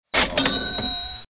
register ding